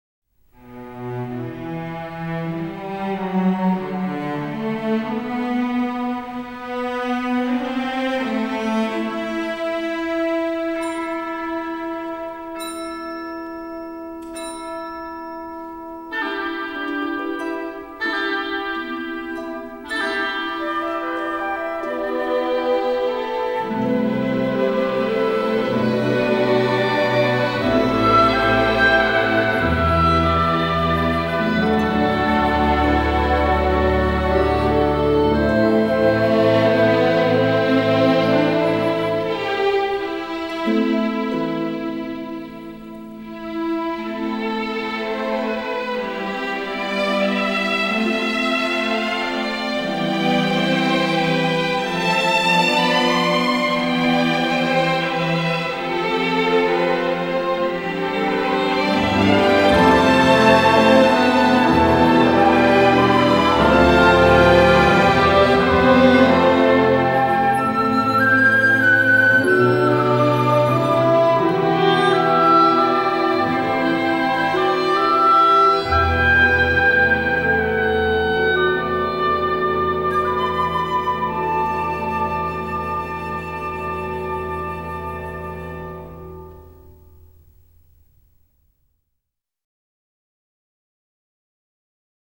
Orchestral Program Music
Children's Adventure music